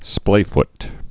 (splāft)